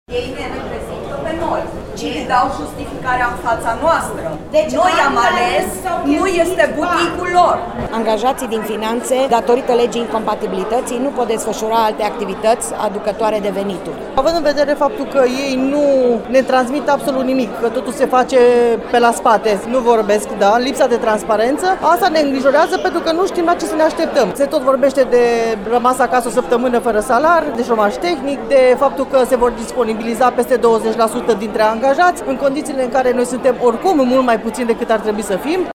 Angajații ANAF Timișoara s-au întâlnit și astăzi pe holurile instituției, pentru a se pune la curent cu noutățile care vin de la Guvern.
Vox-ANAF.mp3